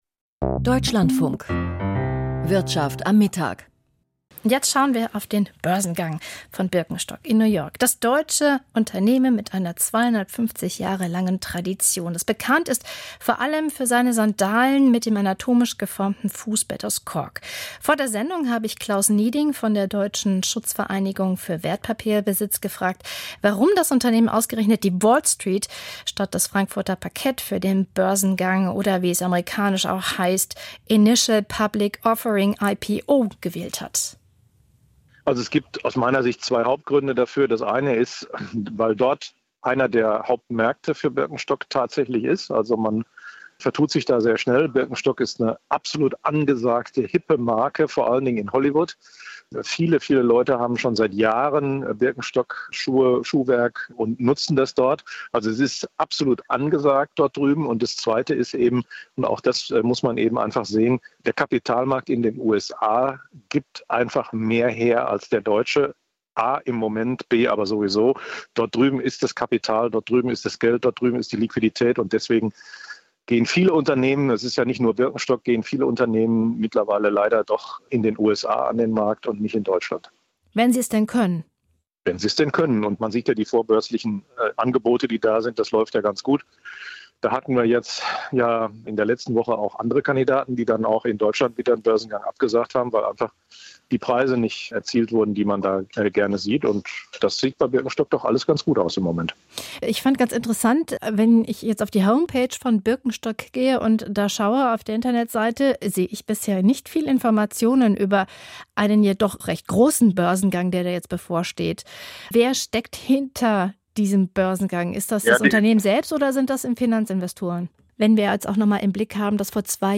Börsengang von Birkenstock - Gespräch